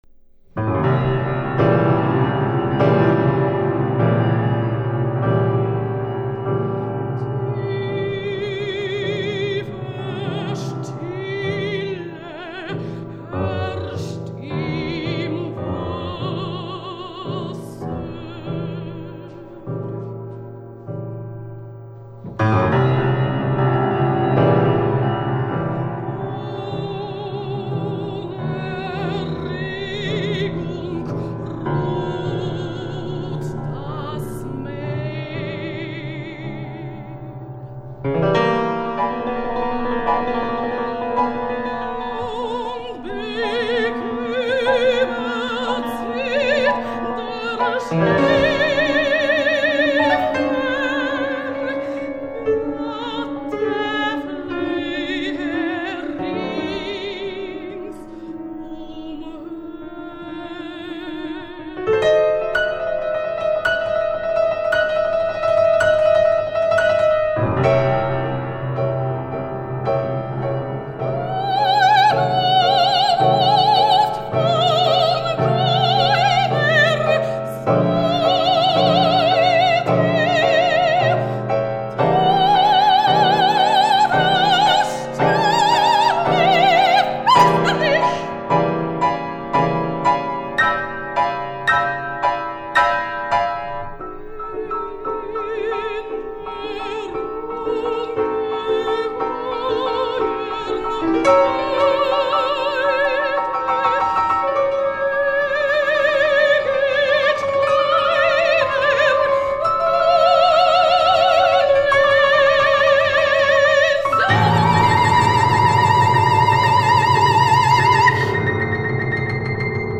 Концерт памяти С.С.Беринского. 20 апреля 2010 года.
"Vier Lieder nach Goethe" - Четыре песни на стихи Гёте для сопрано и фортепиано (соч.1985 года)
сопрано
фортепиано.